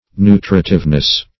Nu"tri*tive*ness, n.